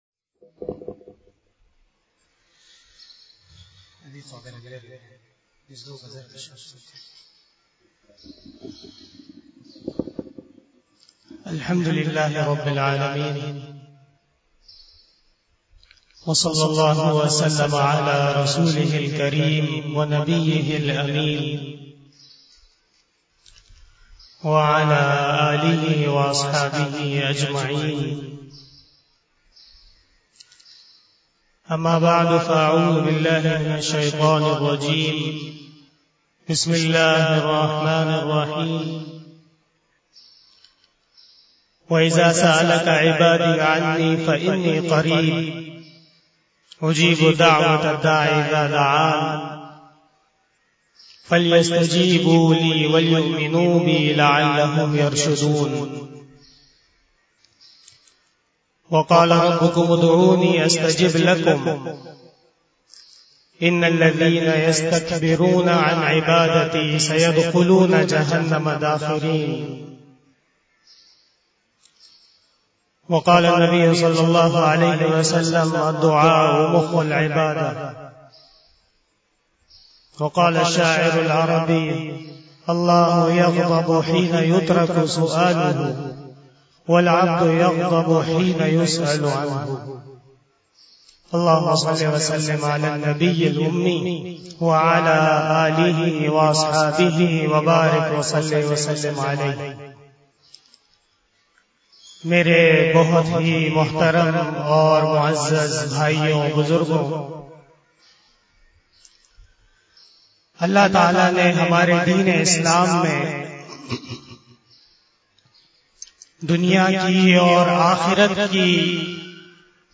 37 BAYAN E JUMA TUL MUBARAK 10 September 2021 (2 Safar 1443H)
02:17 PM 331 Khitab-e-Jummah 2021 --